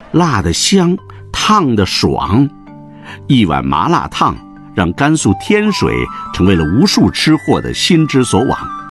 映画のような食のドキュメンタリーナレーション音声
ハイエンドな食のドキュメンタリー、伝統の物語、美食の旅のために設計された、深く響き渡るAI音声で、料理の歴史に命を吹き込みましょう。
ドキュメンタリーナレーション
映画のようなトーン
AI音声合成